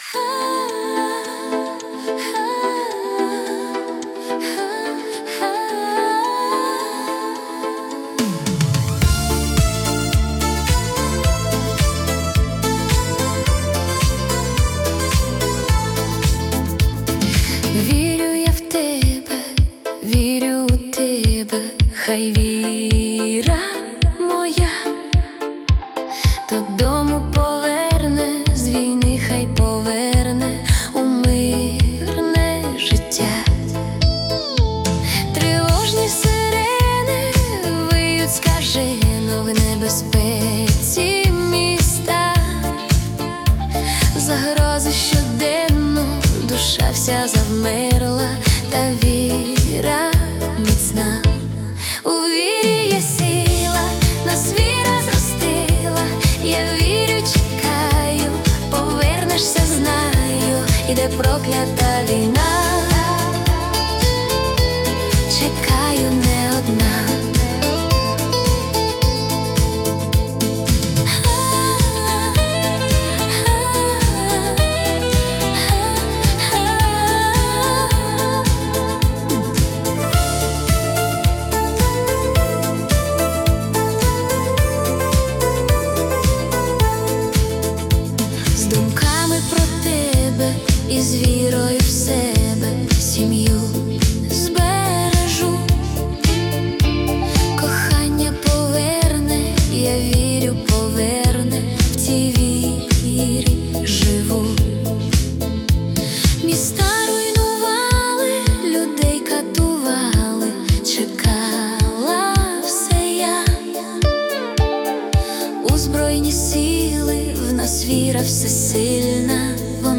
у стилі ретро-попу 80-90-х років (стилістика ВІА)
українська патріотична пісня